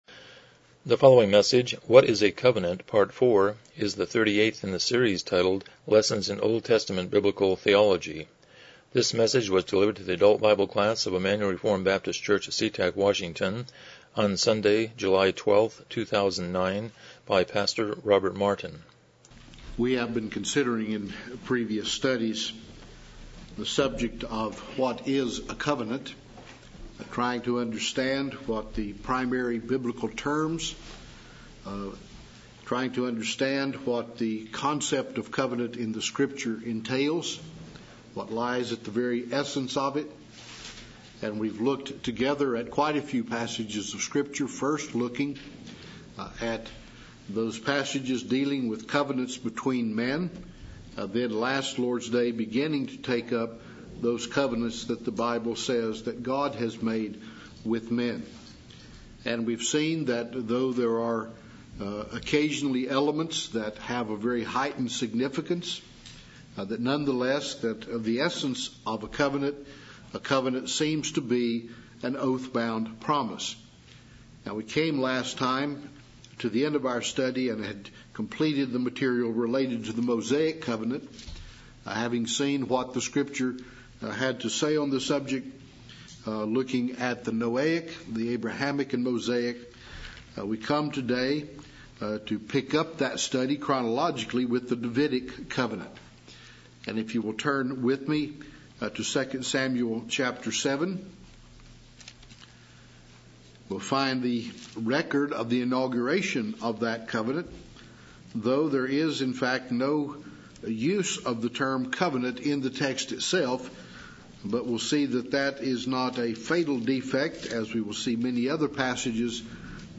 Lessons in OT Biblical Theology Service Type: Sunday School « 83 Romans 7:7-13 The Lord’s Unfailing Word